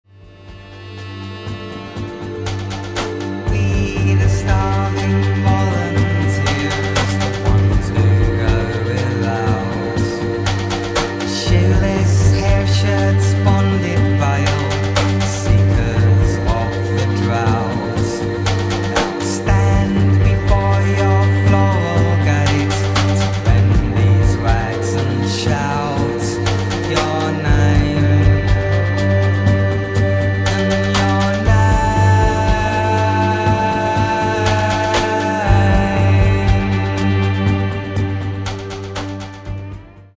bass, drums, percussion, sound processing
guitars, violin, drums and bass
vox inhumana, keyboards
saxophones, flute, bass clarinet, percussion